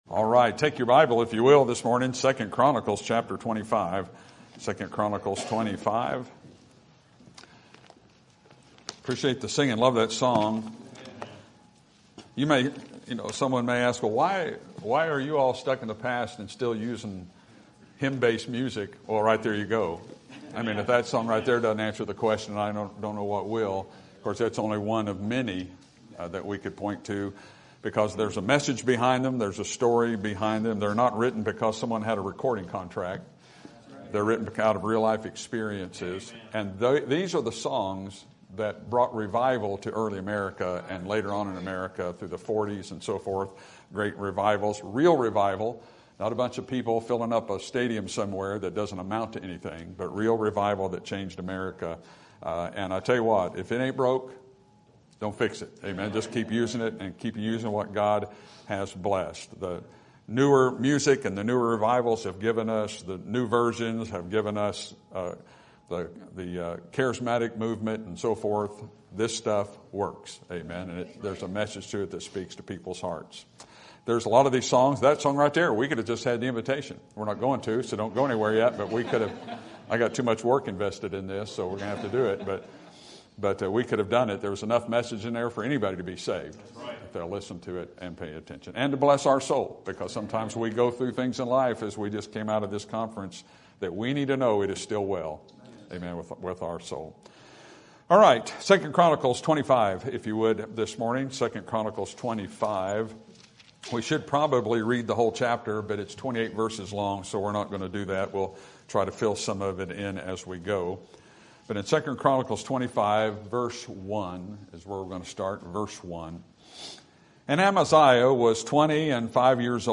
Sermon Topic: General Sermon Type: Service Sermon Audio: Sermon download: Download (26.79 MB) Sermon Tags: 2 Chronicles Amaziah Invest Obedience